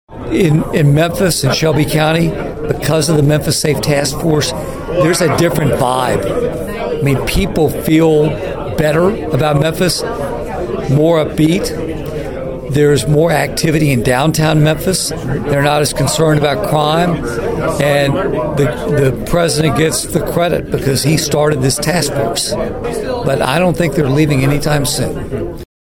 As a Shelby Countian, the Congressman was asked about his thoughts of the task force efforts.(AUDIO)